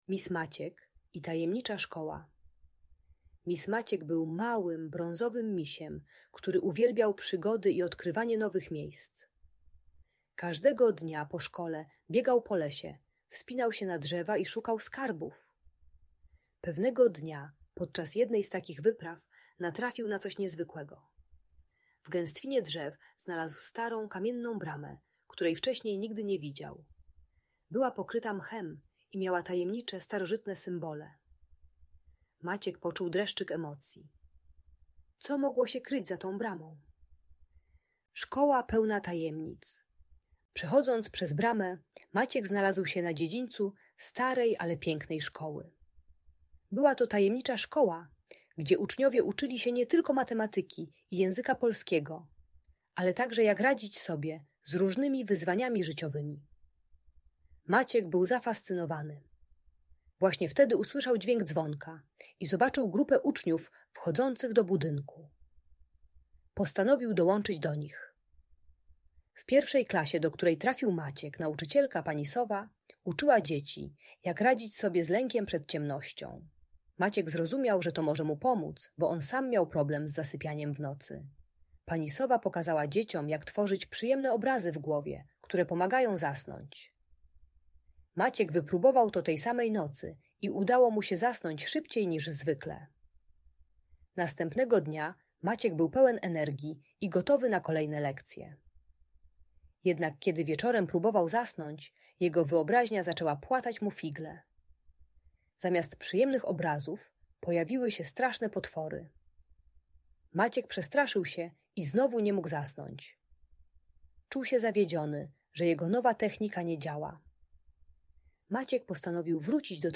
Ta audiobajka o strachu przed ciemnością uczy techniki wizualizacji - tworzenia przyjemnych obrazów w głowie oraz korzystania z lampki nocnej.